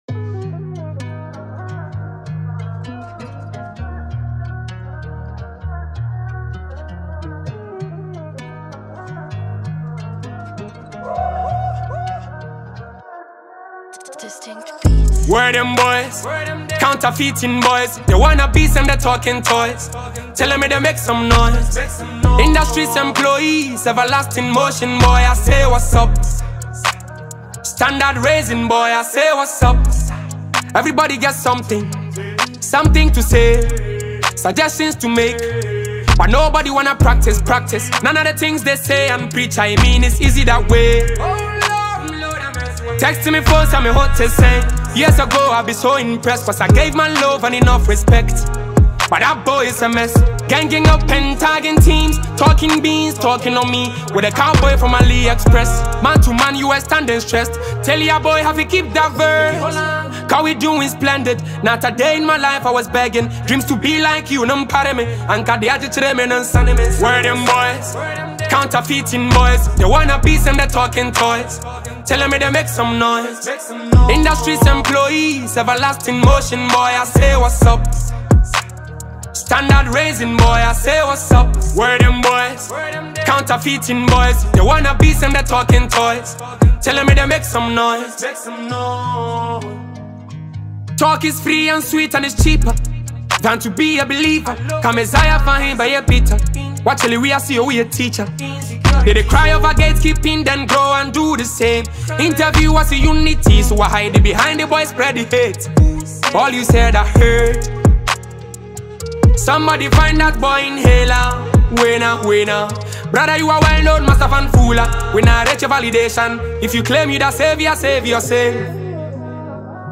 a lively track with a spirit of friendship and resilience
With its hooking rhythm and engaging Afrobeat style
energetic soundscapes